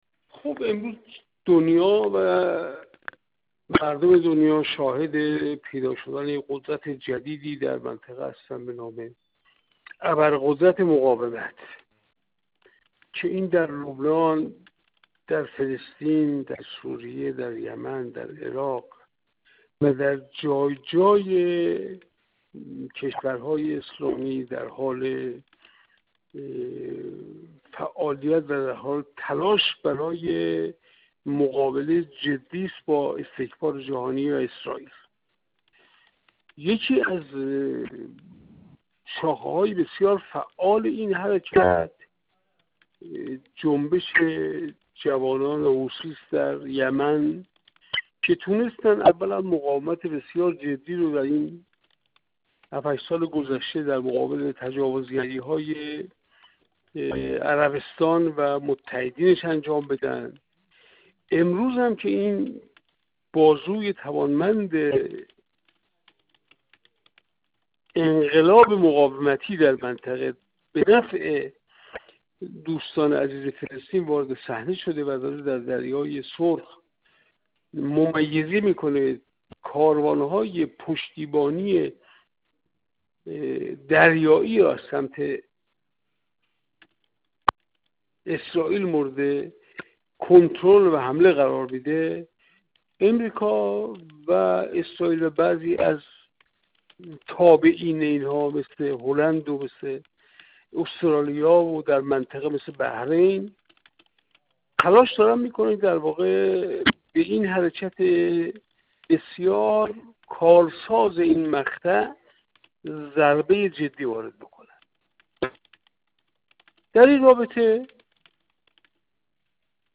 منصور حقیقت‌پور، کارشناس مسائل سیاسی و نایب رئیس کمیسیون امنیت ملی و سیاست خارجی در مجلس دهم، در گفت‌وگو با ایکنا، درباره هدف اصلی حمله آمریکا و انگلیس به یمن در این مقطع زمانی، گفت: امروز مردم دنیا شاهد پیدا شدن قدرت جدیدی در منطقه به نام «ابرقدرت مقاومت» هستند.